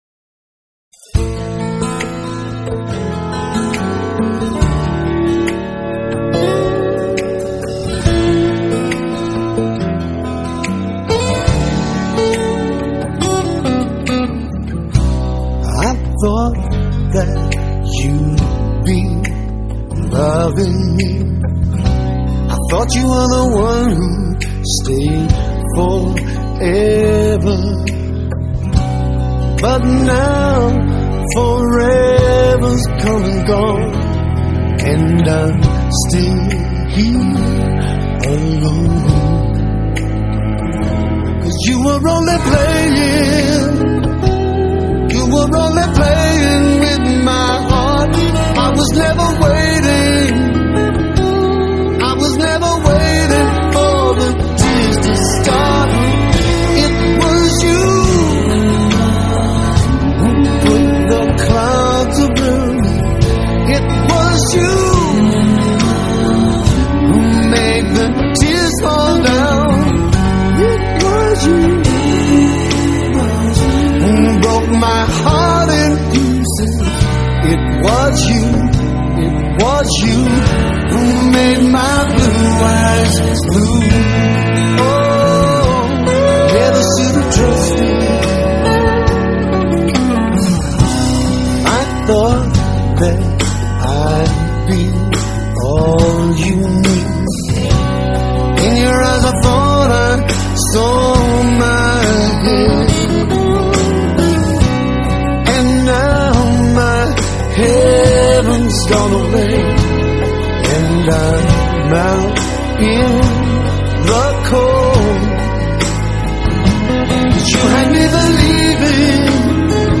Rock, Blues Rock